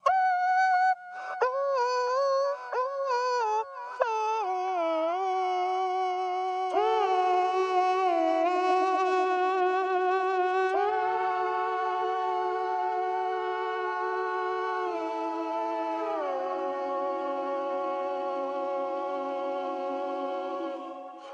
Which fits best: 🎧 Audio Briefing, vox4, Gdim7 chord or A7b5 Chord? vox4